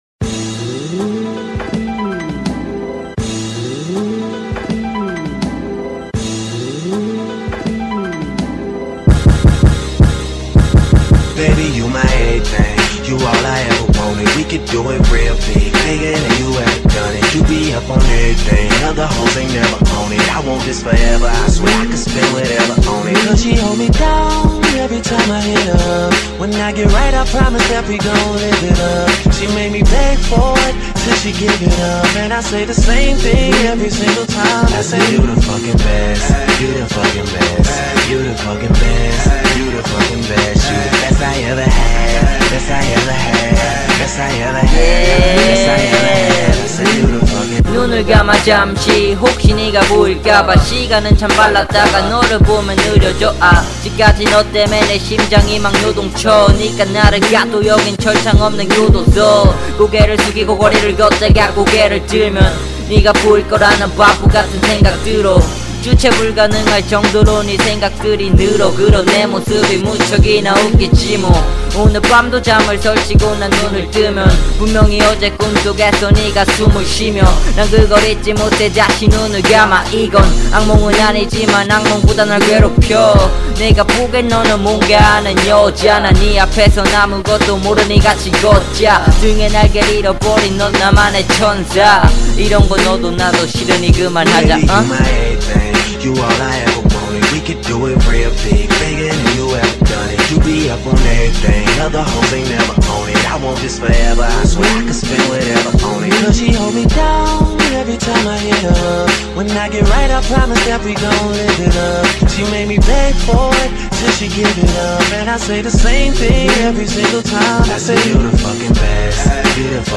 REMIX.